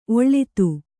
♪ oḷḷitu